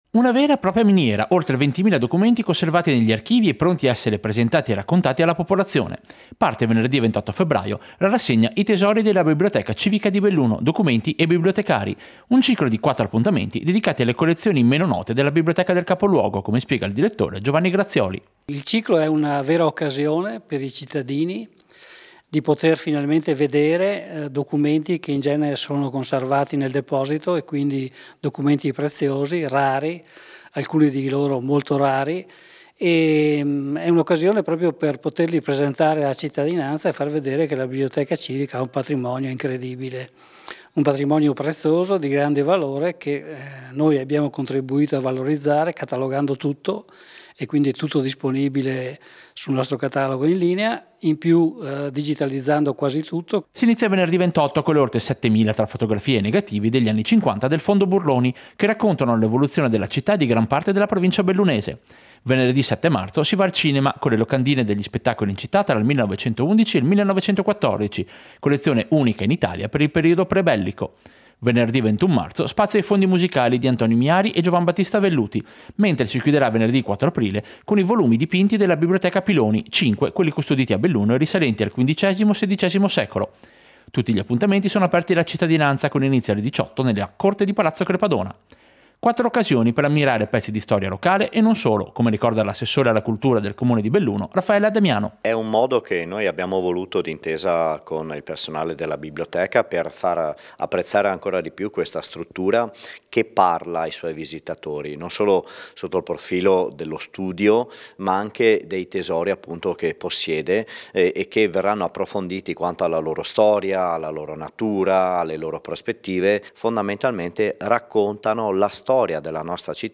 Servizio-Tesori-biblioteca-Belluno.mp3